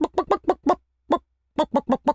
pgs/Assets/Audio/Animal_Impersonations/chicken_cluck_bwak_seq_06.wav at master
chicken_cluck_bwak_seq_06.wav